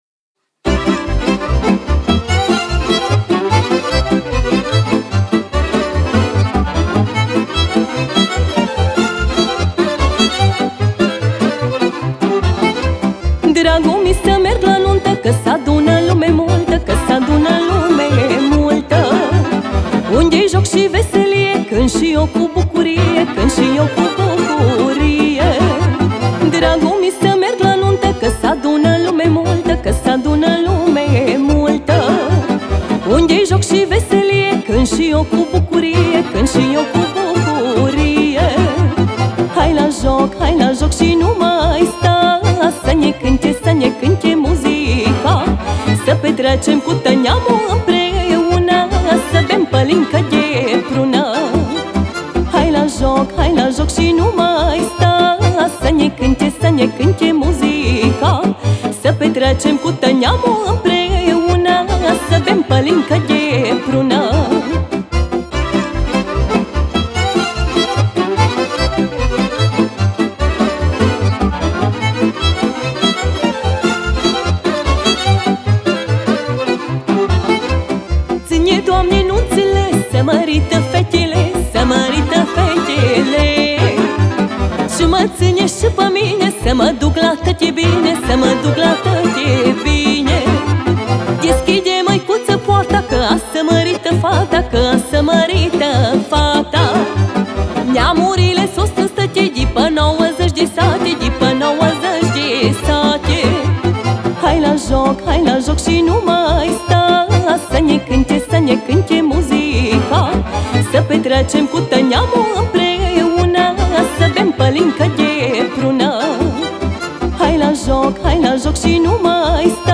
Muzică Populară și de Petrecere